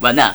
oneup.wav